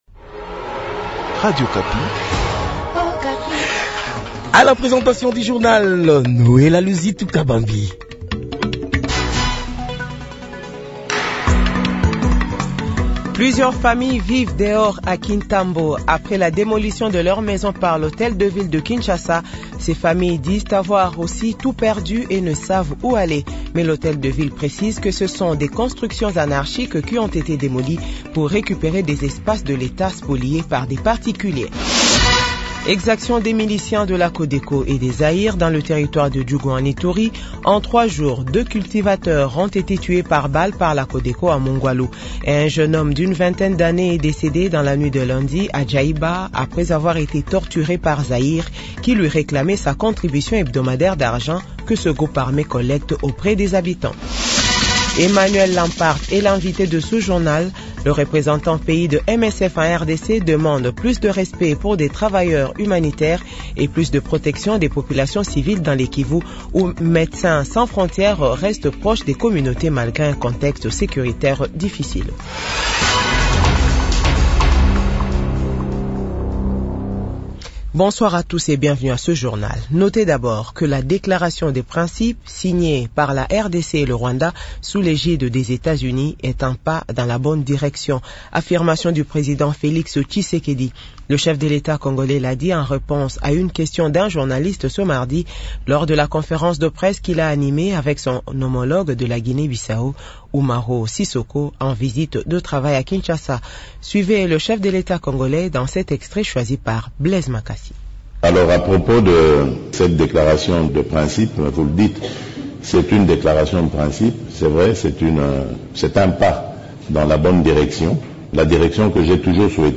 Journal 18h